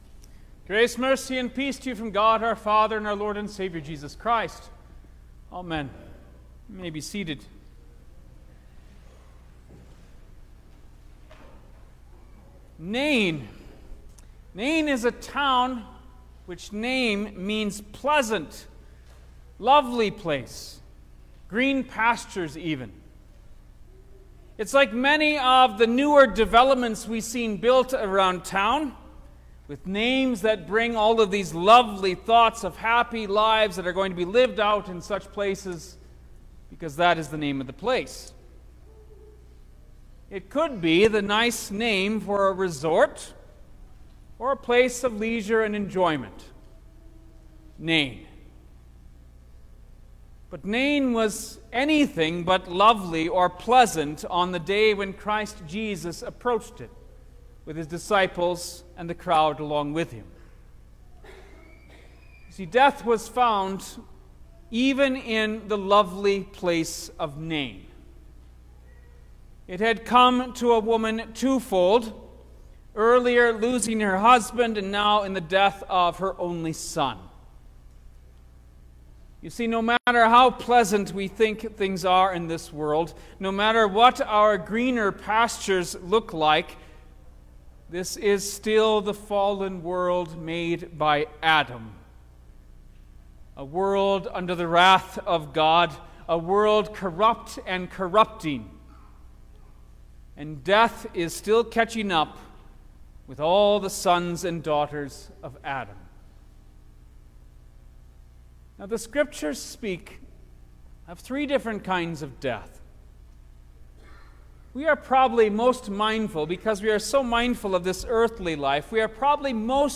October-2_2022_The-Sixteenth-Sunday-after-Trinity_Sermon-Stereo.mp3